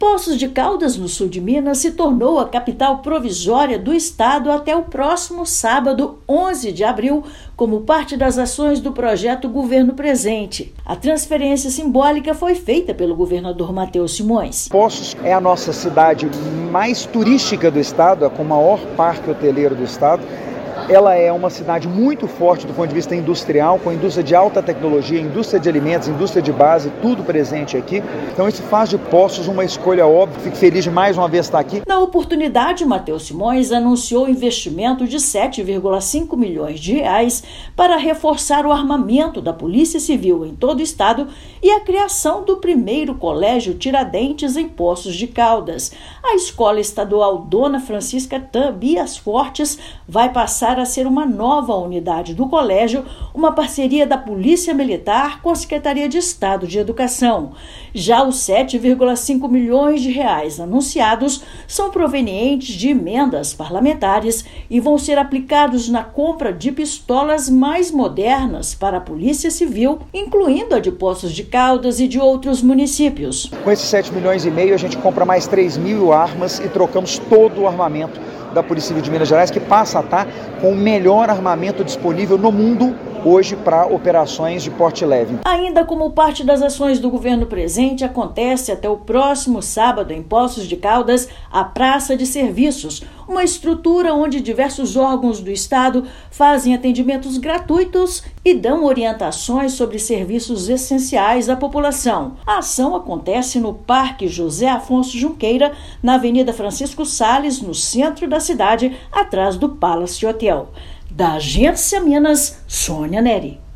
Novos equipamentos vão melhorar condições de trabalho dos policiais em todo o estado e a cidade de mais de 160 mil habitantes ganhará primeira unidade do colégio da Polícia Militar. Ouça matéria de rádio.